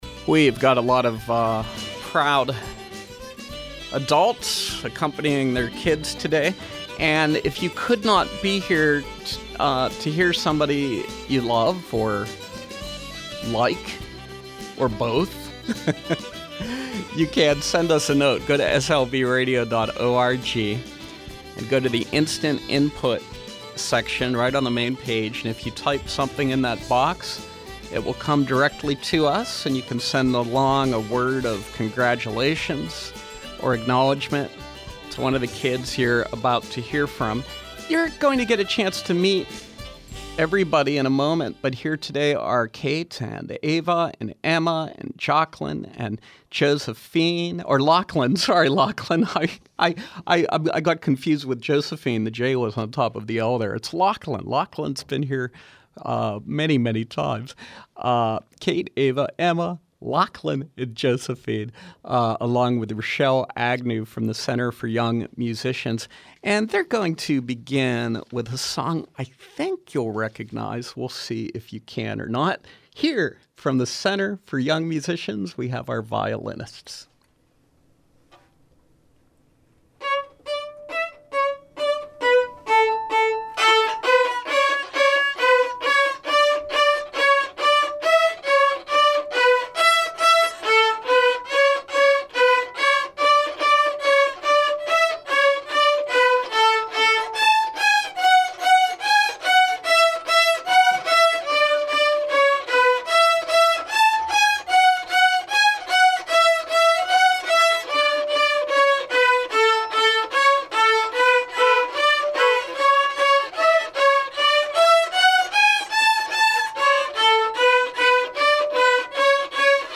From 06/15/2013: Violinists from The Center for Young Musicians performing live on SLB.